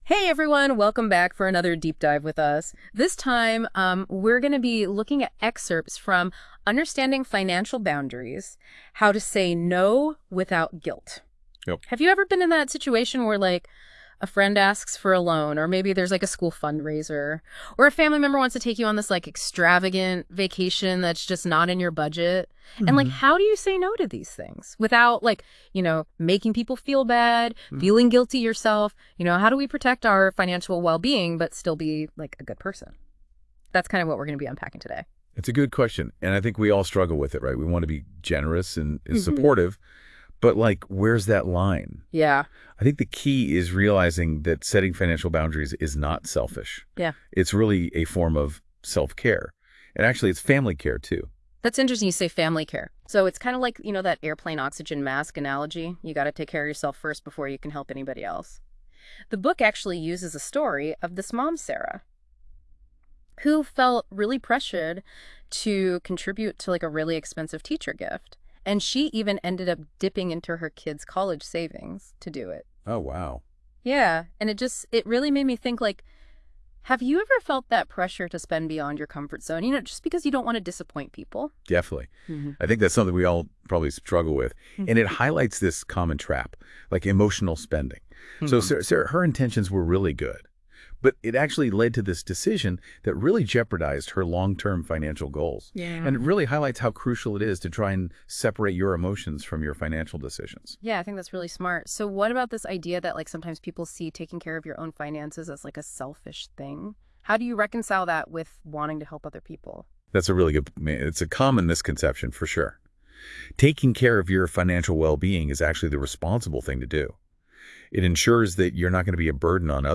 I used AI to turn one of my articles into a podcast episode 🤯